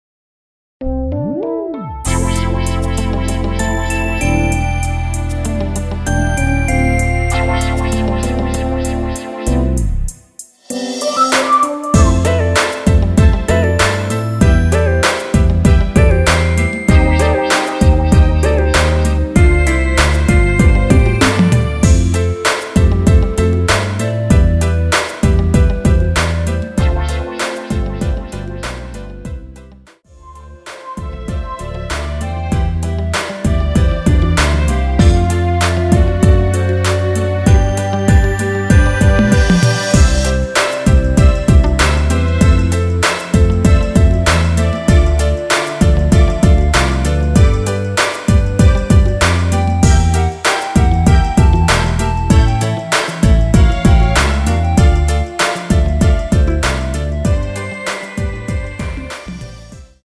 원키 멜로디 포함된 MR 입니다.(미리듣기 참조)
노래방에서 노래를 부르실때 노래 부분에 가이드 멜로디가 따라 나와서
앞부분30초, 뒷부분30초씩 편집해서 올려 드리고 있습니다.
중간에 음이 끈어지고 다시 나오는 이유는